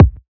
KICK 13.wav